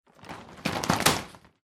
На этой странице собраны звуки традиционных японских ширм – редкие и атмосферные аудиозаписи.
Звук сложили одну дверку у ширмы